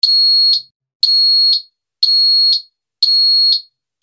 SIRENA 8 TONOS
Sirena con 8 tonos diferentes
Tono_6